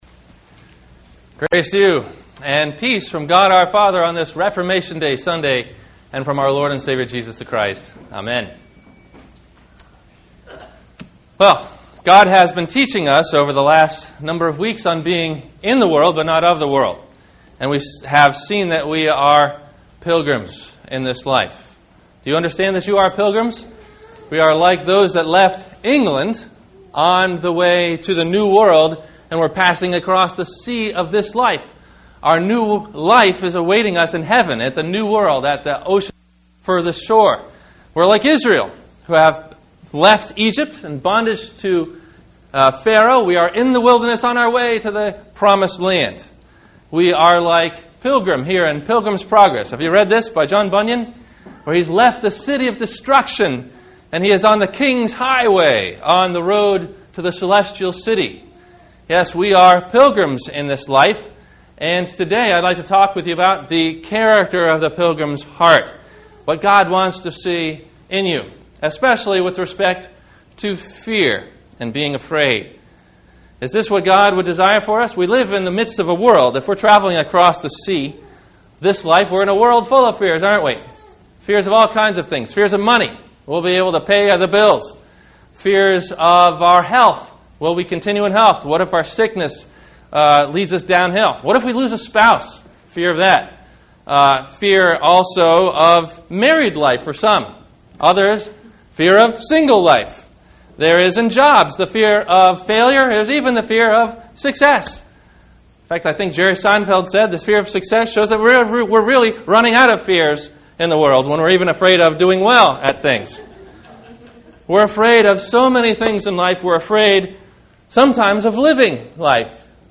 No Fear Save One - Sermon - October 25 2009 - Christ Lutheran Cape Canaveral